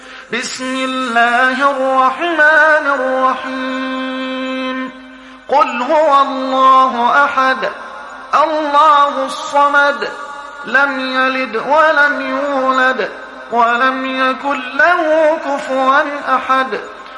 تحميل سورة الإخلاص mp3 بصوت محمد حسان برواية حفص عن عاصم, تحميل استماع القرآن الكريم على الجوال mp3 كاملا بروابط مباشرة وسريعة